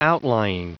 Prononciation du mot outlying en anglais (fichier audio)
Prononciation du mot : outlying